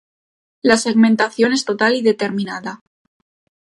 seg‧men‧ta‧ción
/seɡmentaˈθjon/